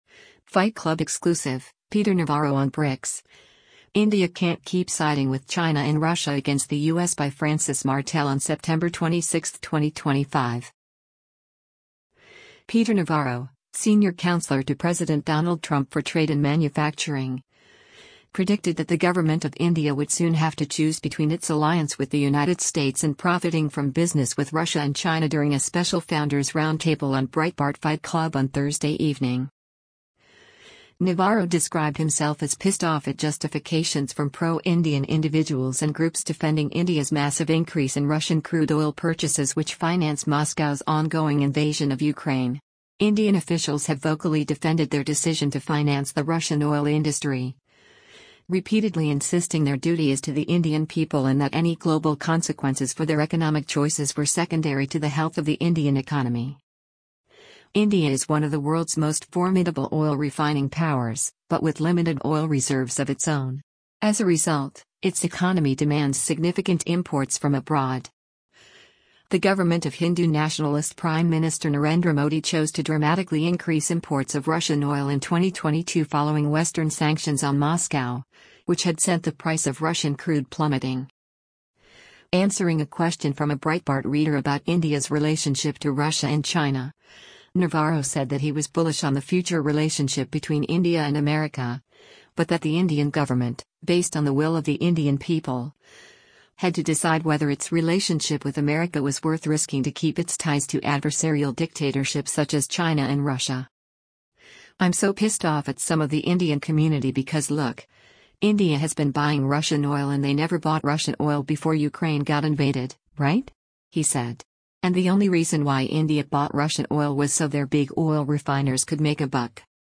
Peter Navarro, senior counselor to President Donald Trump for trade and manufacturing, predicted that the government of India would soon have to choose between its alliance with the United States and profiting from business with Russia and China during a special Founders Roundtable on Breitbart Fight Club on Thursday evening.
Answering a question from a Breitbart reader about India’s relationship to Russia and China, Navarro said that he was “bullish” on the future relationship between India and America, but that the Indian government, based on the will of the Indian people, had to decide whether its relationship with America was worth risking to keep its ties to adversarial dictatorships such as China and Russia.